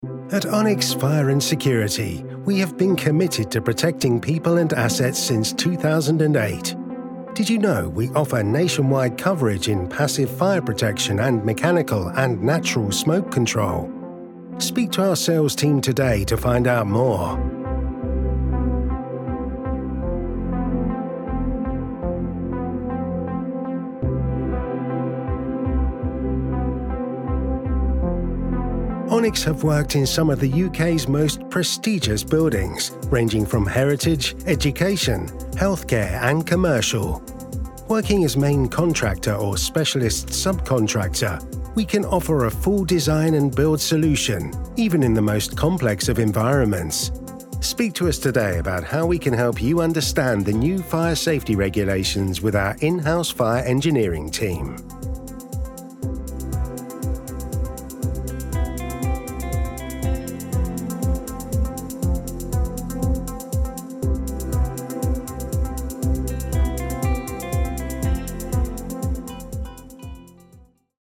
Englisch (Britisch)
Tief, Natürlich, Zugänglich, Freundlich, Warm
Erklärvideo